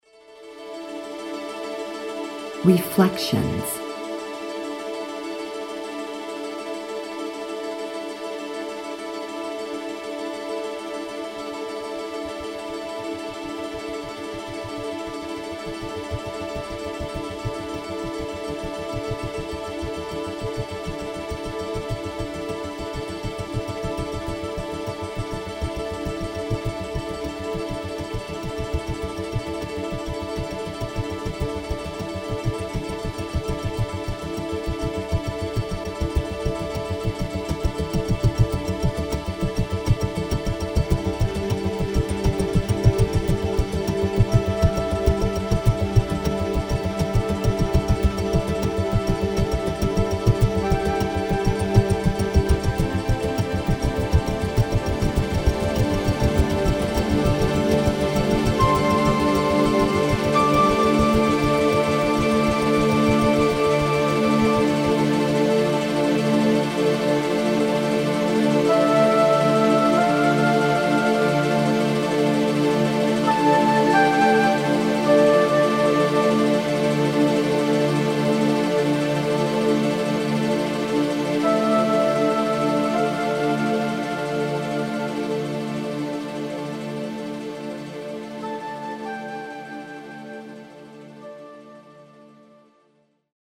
Léčebná hudba je starobylým uměním.
Relaxace, Meditace, Relaxační a Meditační hudba
Nástroje: syntetizátor a exteriérové zvuky přírody.
Verbální vedení: Neverbální